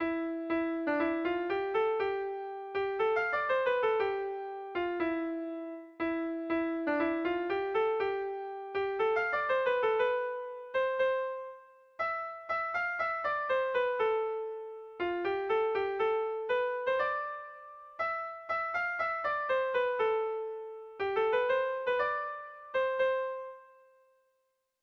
Bertso melodies - View details   To know more about this section
Erlijiozkoa
A1A2B1B2